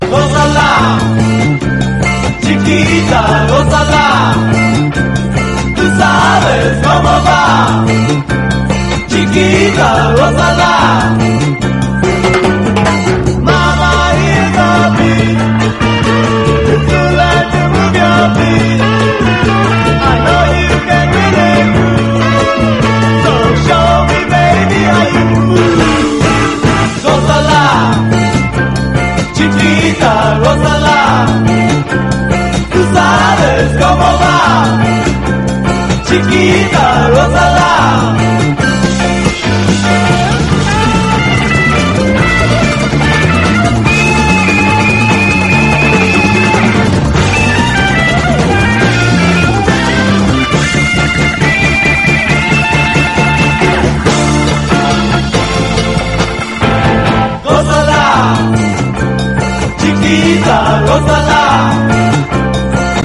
MODERN SOUL / DISCO / DISCO BOOGIE
両面ナイスなモダン・ソウル・ダンサー/ブギー・ソウル！
柔らかなエレピが包むメロウな質感のこみ上げ系モダン・ソウル・ダンサー
女性コーラスとの掛け合いも華やいだ雰囲気！
ちょいオールド・タイミーな雰囲気のアッパー・ブギー・ソウル